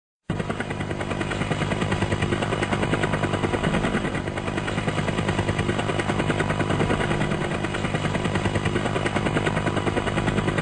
Sonido de Helicóptero de Guerra Volando.wma